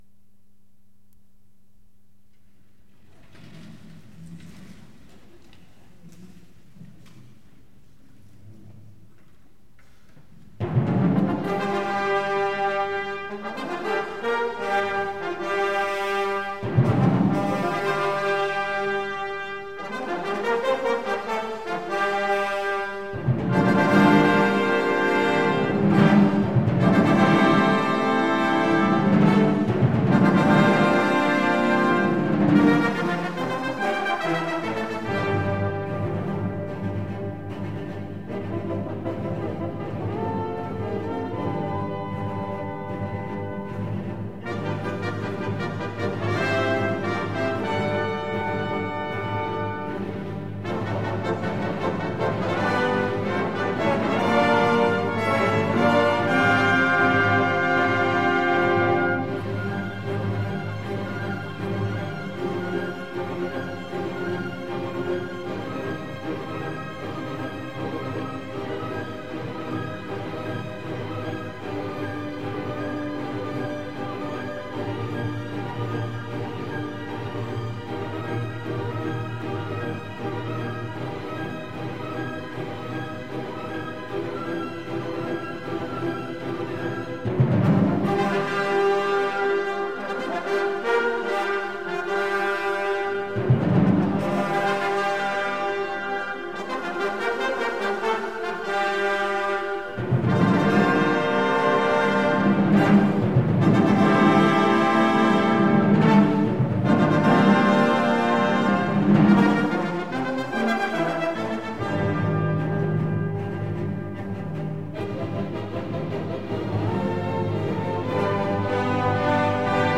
Advanced Full Orchestra